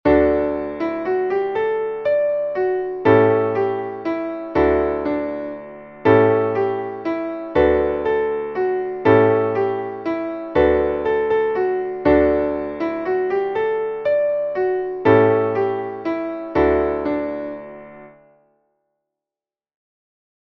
Traditionelles Kinderlied / Volkslied